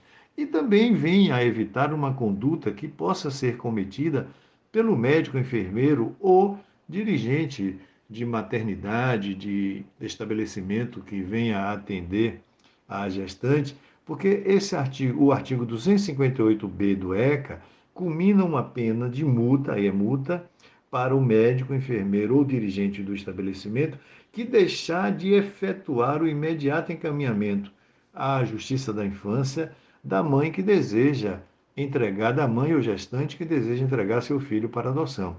Caso o profissional de saúde deixe de encaminhar a mãe/gestante à Vara da Infância e Juventude, estará agindo ilegalmente. O Desembargador Salomão Resedá explica mais sobre o assunto: